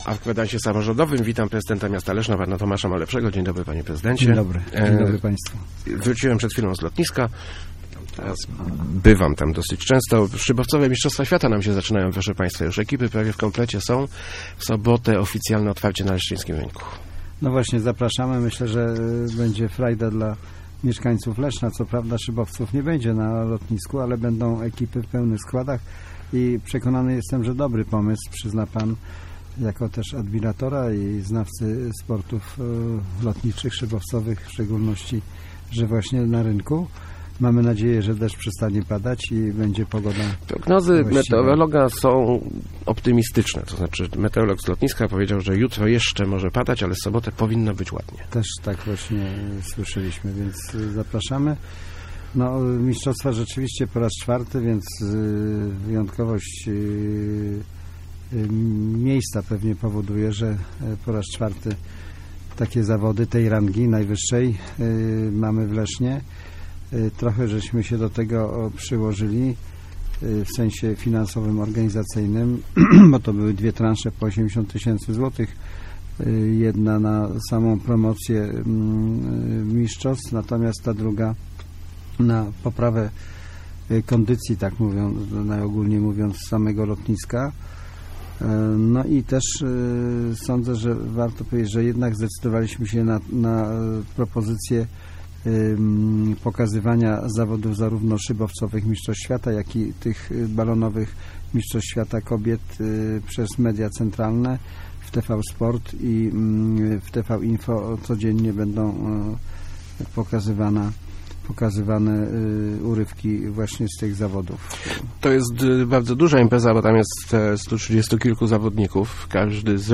Gościem Kwadransa był prezydent Tomasz Malepszy ...